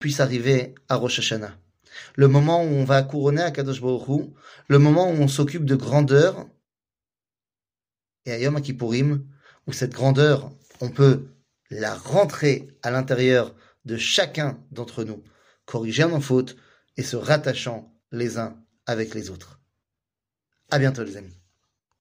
שיעור מ 18 ספטמבר 2022 05MIN הורדה בקובץ אודיו MP3 (344.94 Ko) הורדה בקובץ וידאו MP4 (1.89 Mo) TAGS : שיעורים קצרים